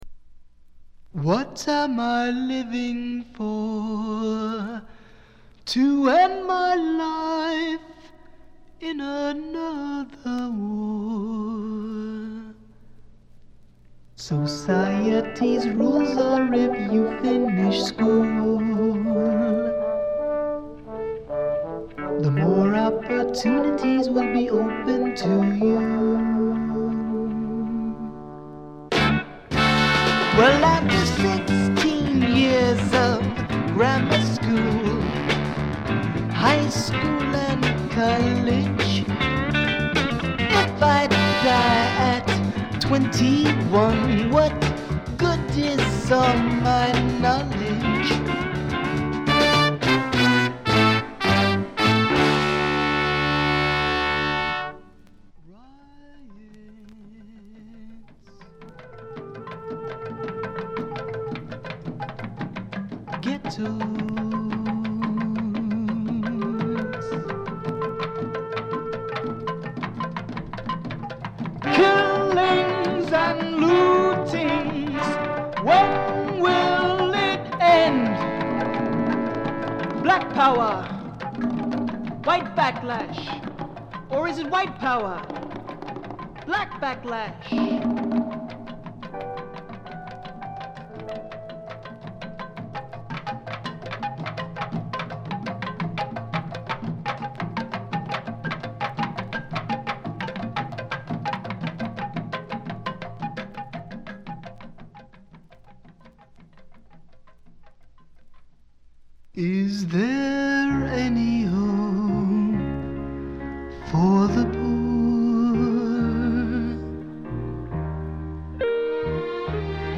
黒人臭さのまったくないヴォイスがまた素晴らしいです。
試聴曲は現品からの取り込み音源です。